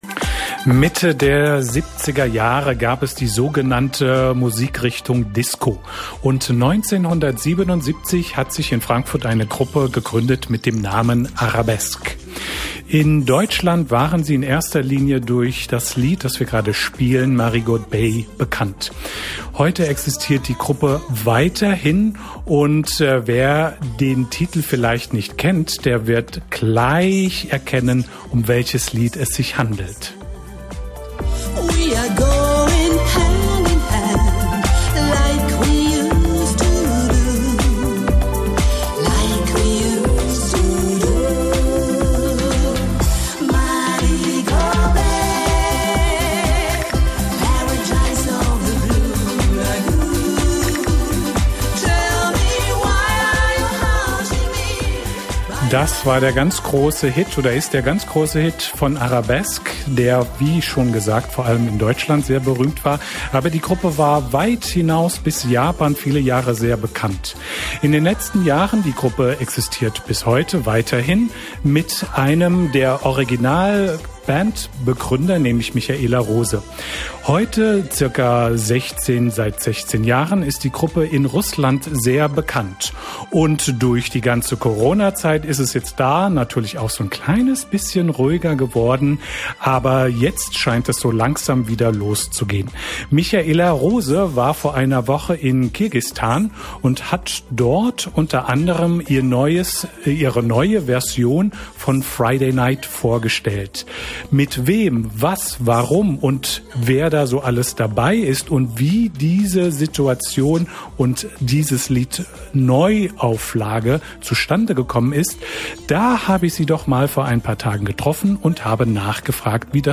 Oriental-Mix
1255_arabesque.mp3